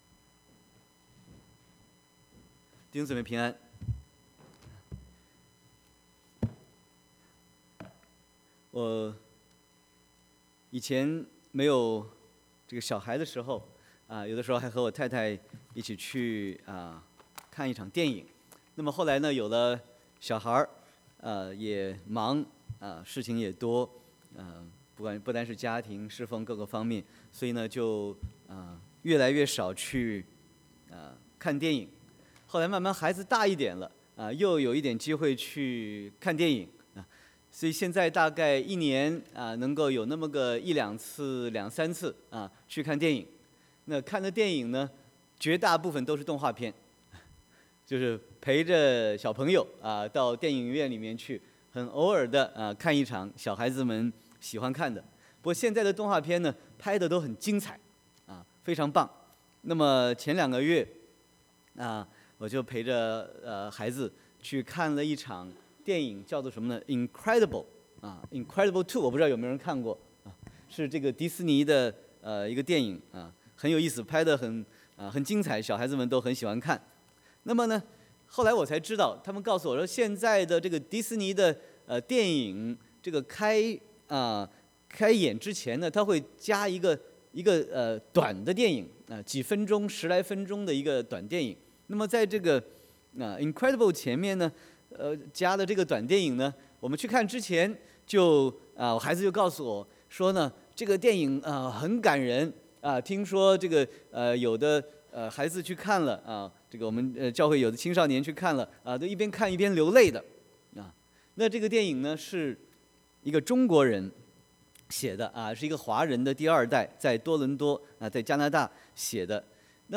Sermon 11/25/2018